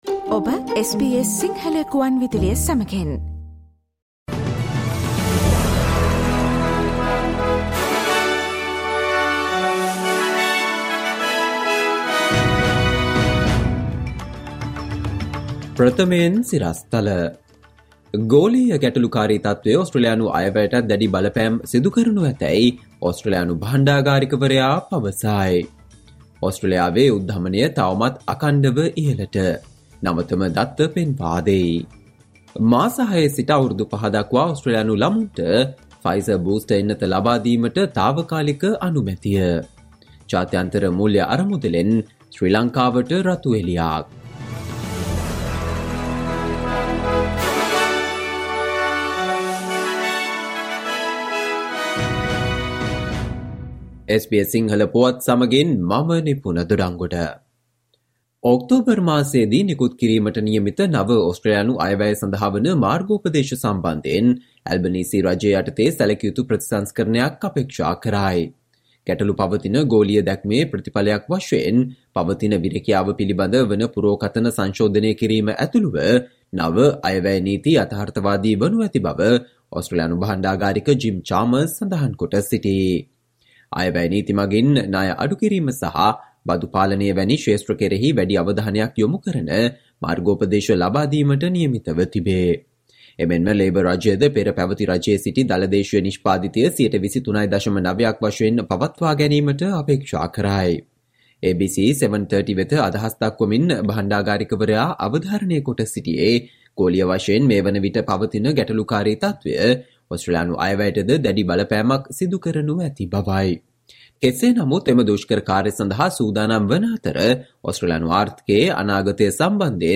Listen to the SBS Sinhala Radio news bulletin on Friday 30 September 2022